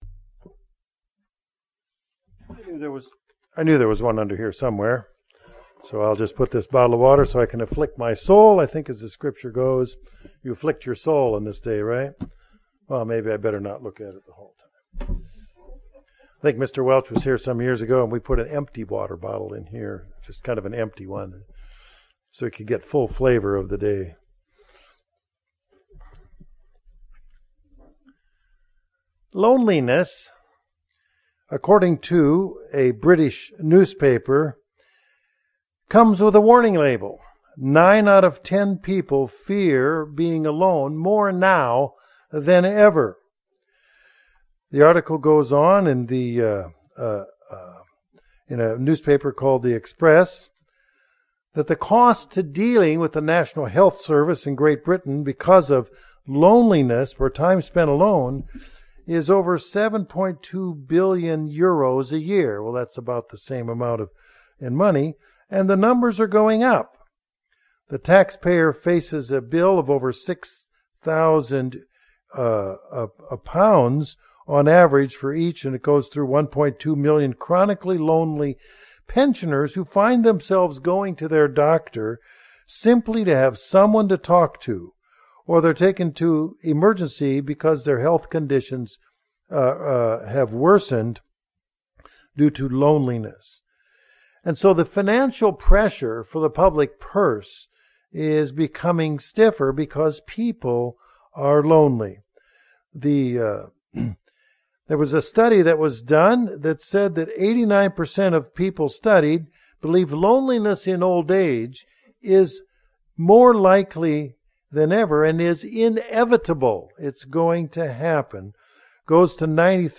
Sermons
Given in Northwest Arkansas